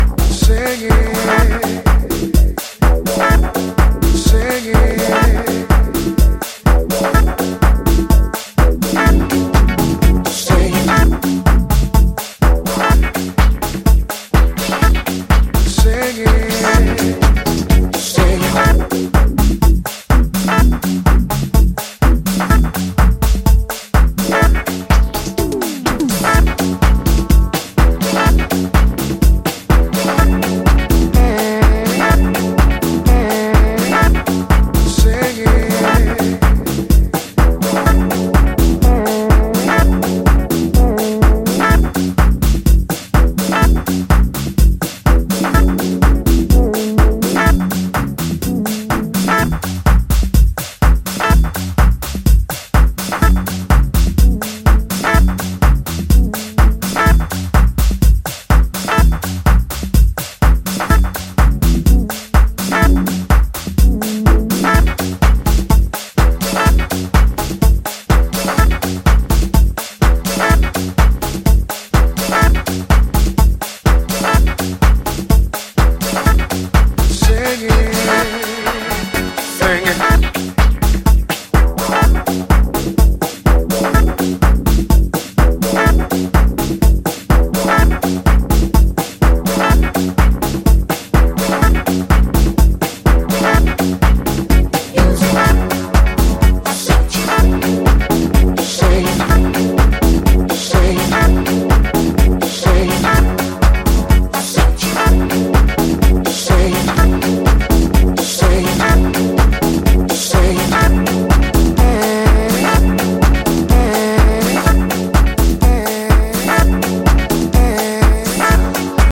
UK house
serious disco vibes
Disco House